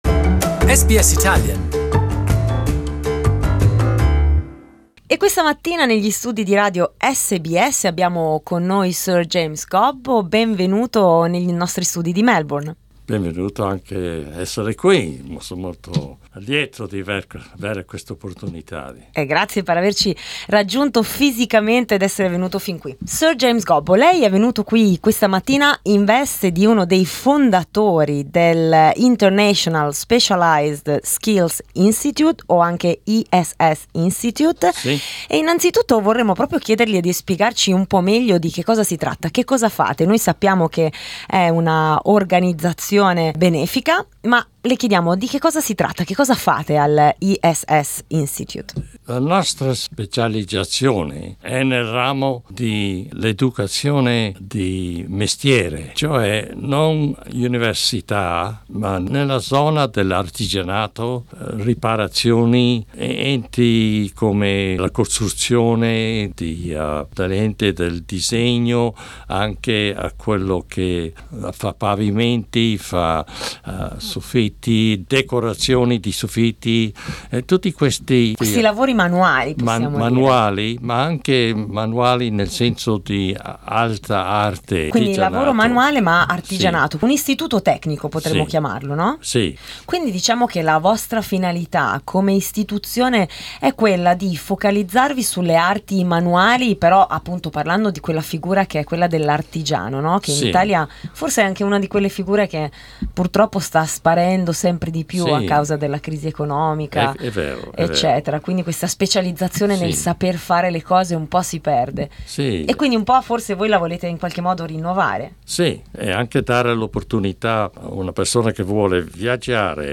Per capire meglio i dettagli dell'iniziativa e a chi è rivolta abbiamo parlato con Sir James Gobbo, ex governatore del Victoria e membro fondatore dell'ISSI.